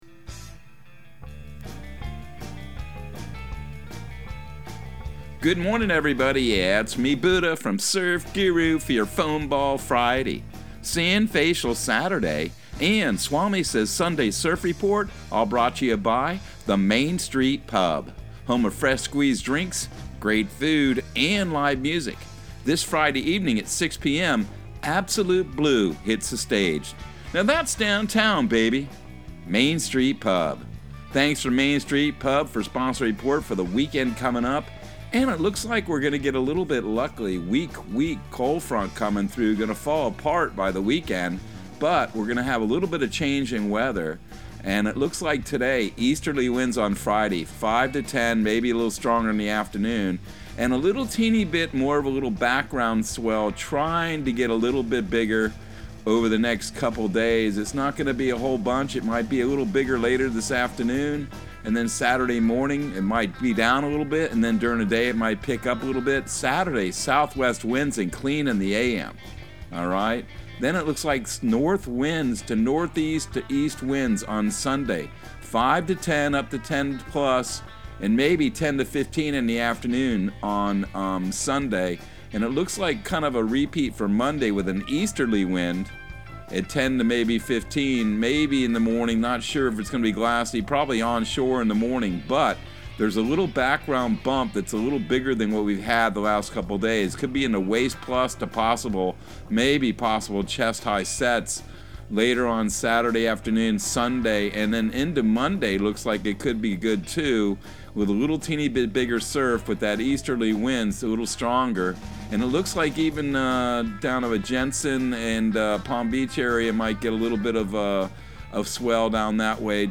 Surf Guru Surf Report and Forecast 06/17/2022 Audio surf report and surf forecast on June 17 for Central Florida and the Southeast.